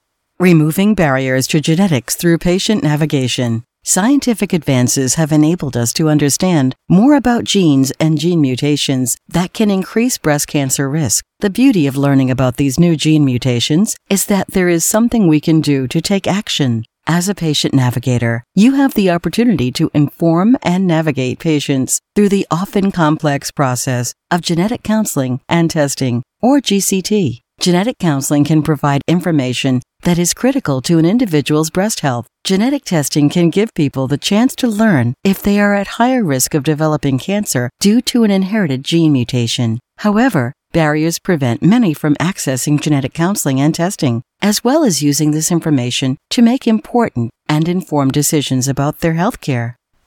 Female
My voice is very versatile, warm, conversational, and real.
E-Learning
Words that describe my voice are Warm, Conversational, Sophisticated.